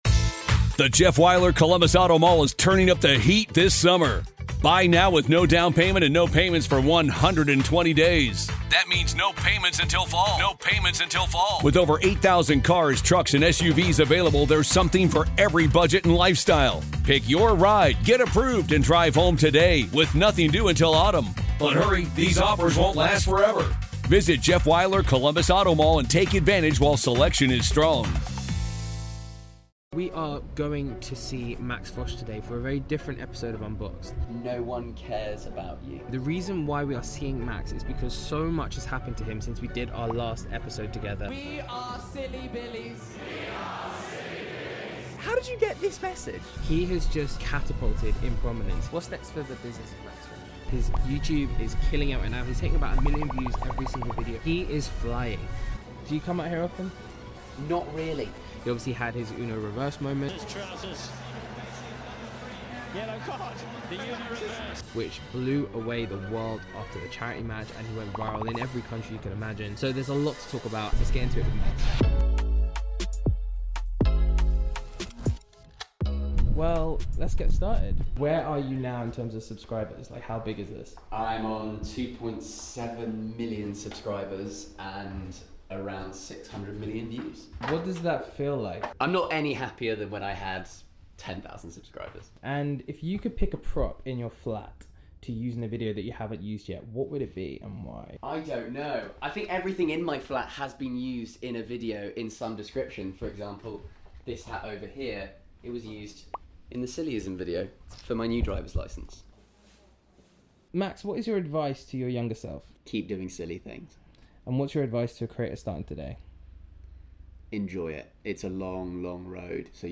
I asked Max Fosh 32 silly questions (in his kitchen)
This week, we take Unboxed to Max Fosh's kitchen! We hope you enjoy this follow-up interview.